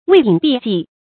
畏影避跡 注音： ㄨㄟˋ ㄧㄥˇ ㄅㄧˋ ㄐㄧˋ 讀音讀法： 意思解釋： 比喻庸人自擾，不明事理。